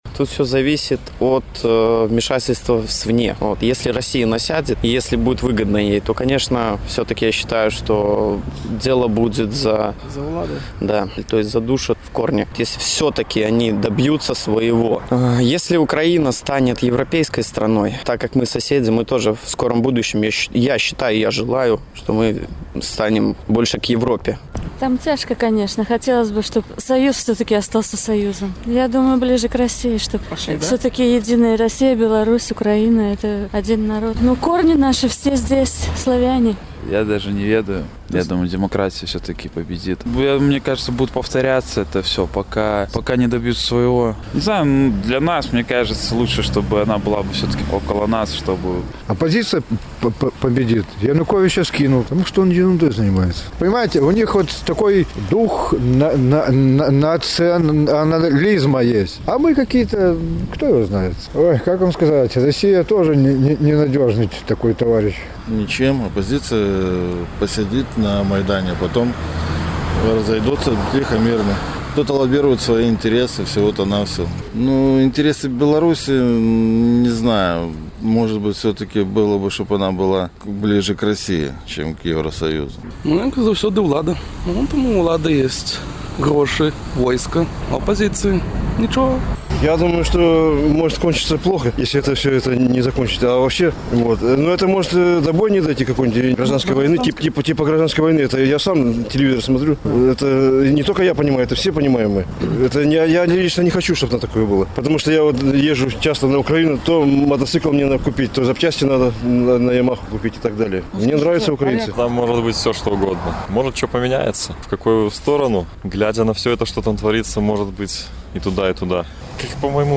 Адказваюць гарадзенцы
Што больш выгадна для Беларусі — мець у суседзях Украіну праэўрапейскую дэмакратычную, ці — прарасейскую аўтарытарную? З такім пытаньнем наш карэспандэнт зьвяртаўся да гарадзенцаў.